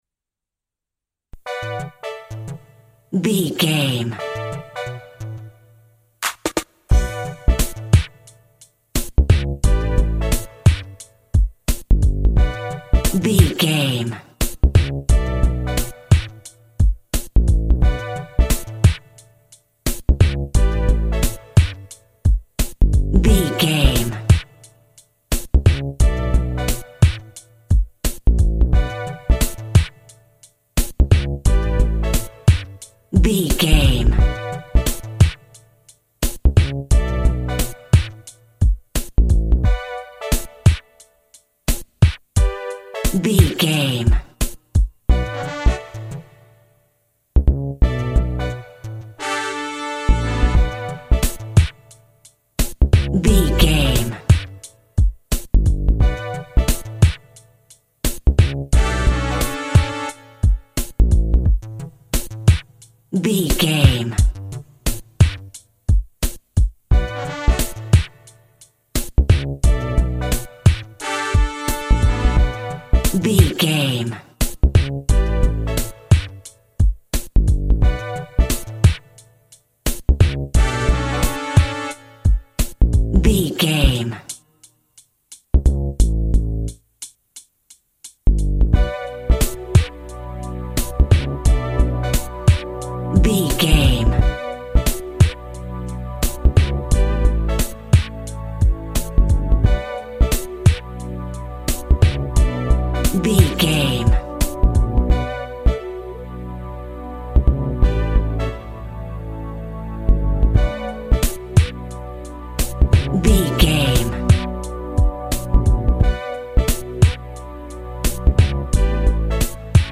Aeolian/Minor
DOES THIS CLIP CONTAINS LYRICS OR HUMAN VOICE?
synth lead
synth bass
hip hop synths